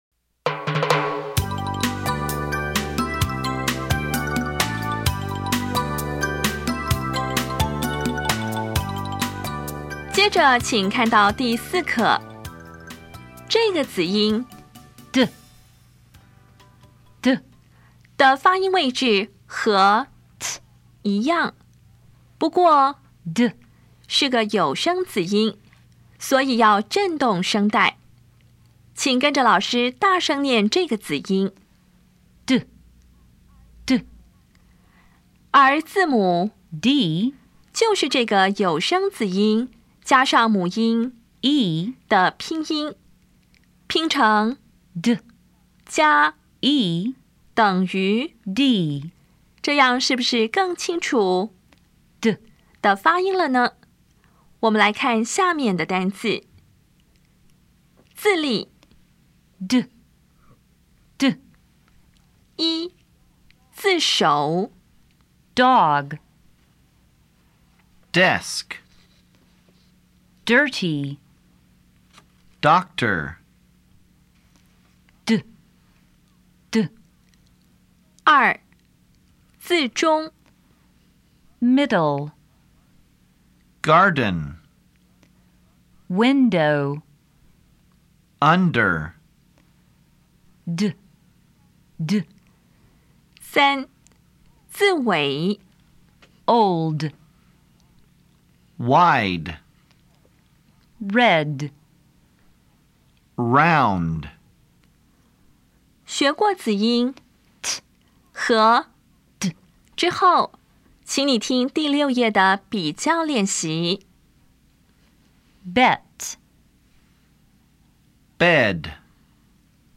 当前位置：Home 英语教材 KK 音标发音 子音部分-2: 有声子音 [d]
音标讲解第四课
比较[t] 与 [d]    [t](无声) [d](有声)
Listening Test 2